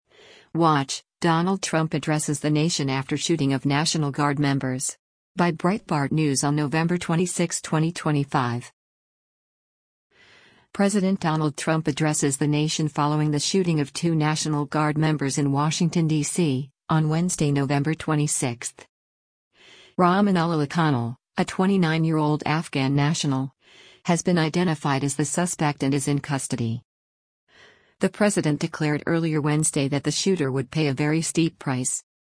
President Donald Trump addresses the nation following the shooting of two National Guard members in Washington, DC, on Wednesday, November 26.